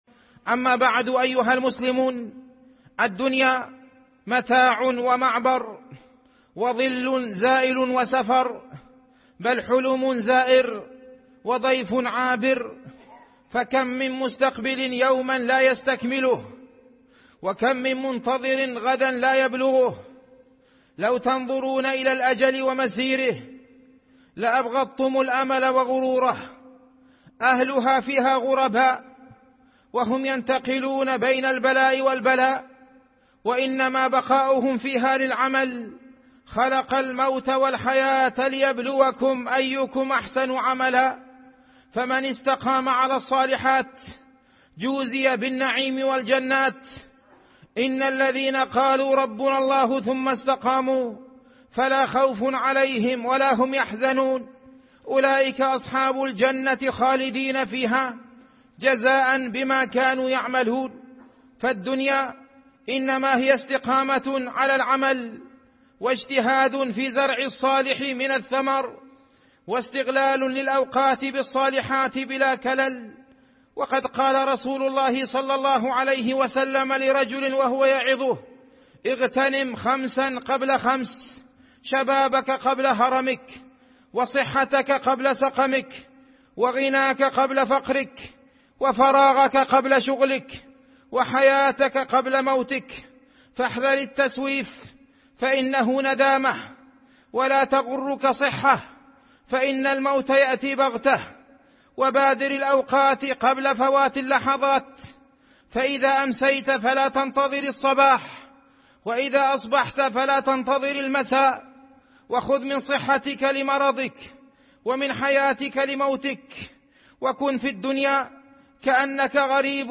موعظة